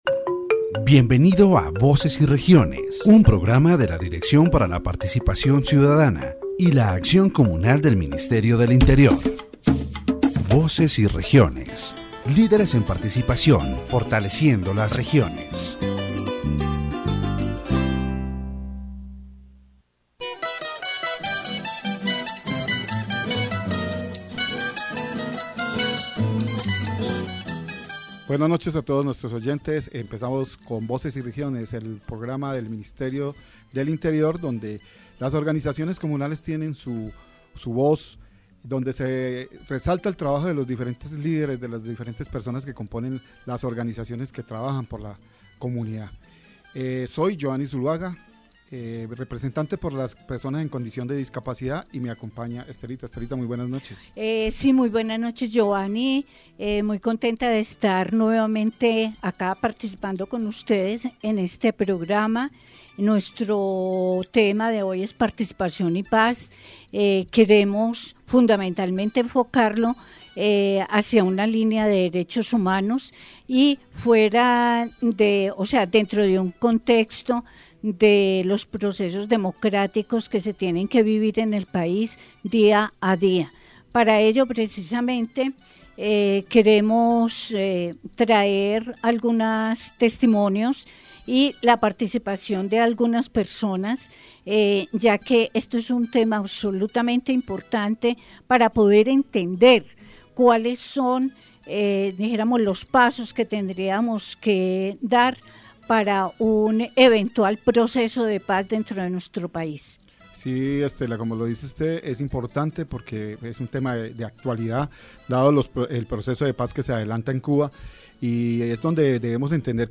In this section of the Voces y Regiones program, the topic of peace and participation in Colombia is discussed, with a special focus on the department of Quindío. Through interviews with community leaders, the program highlights their understanding of peace and their commitment to the development of their communities.